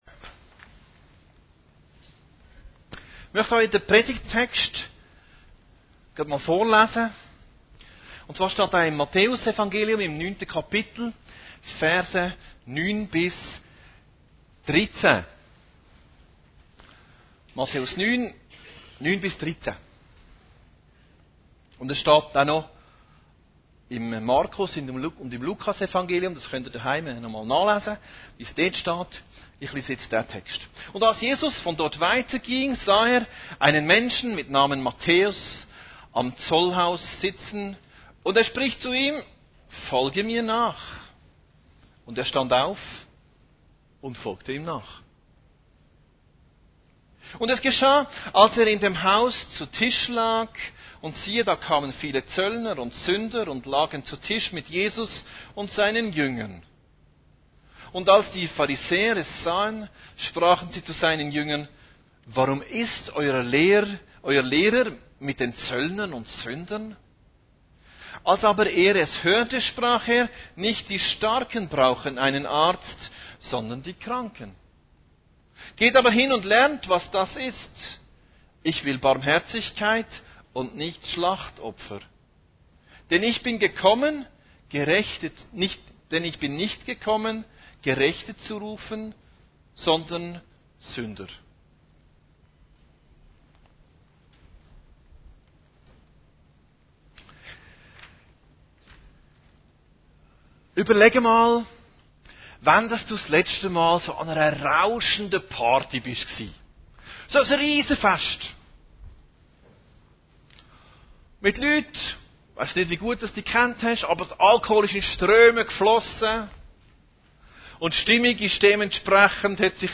Predigten Heilsarmee Aargau Süd – Jesus in der Welt